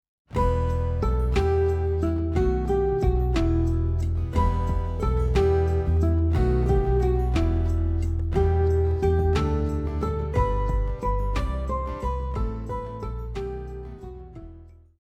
• Guitar arrangement